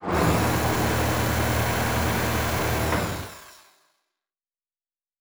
pgs/Assets/Audio/Sci-Fi Sounds/Mechanical/Servo Big 1_1.wav at master
Servo Big 1_1.wav